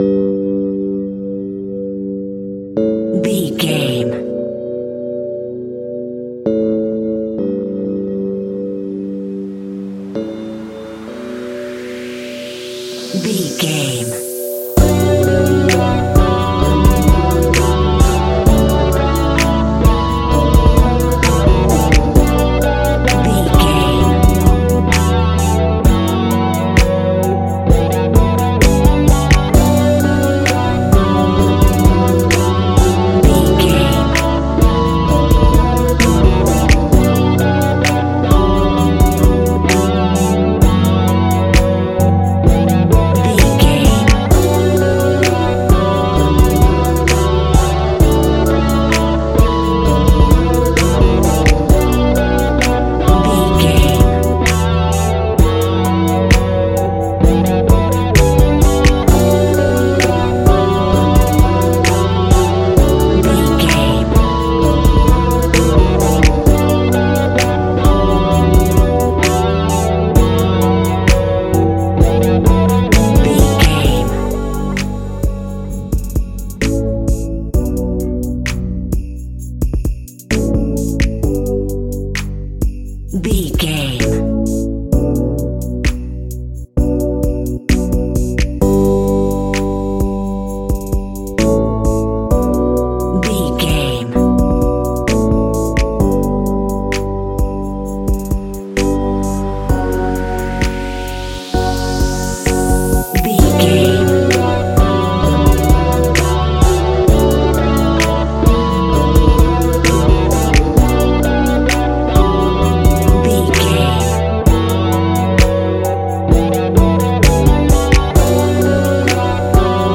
Ionian/Major
B♭
chilled
Lounge
sparse
new age
chilled electronica
ambient
atmospheric